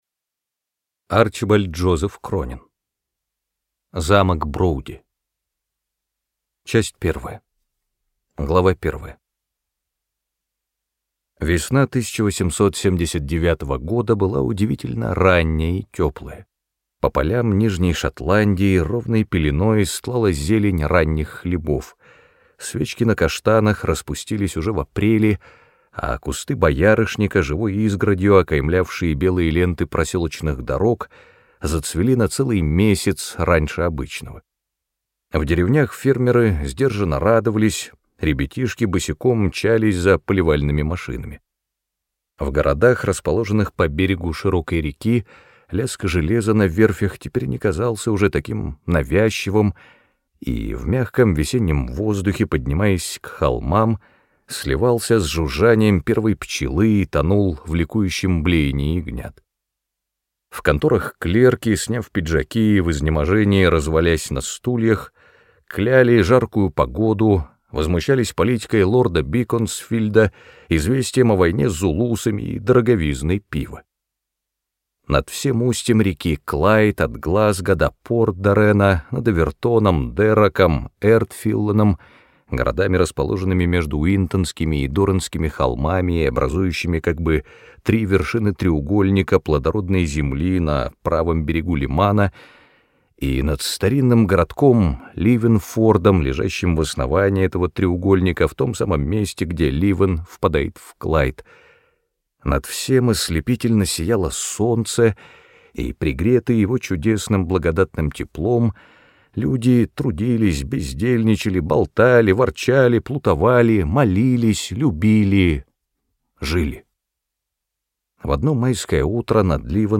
Аудиокнига Замок Броуди - купить, скачать и слушать онлайн | КнигоПоиск